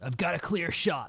Voices / Male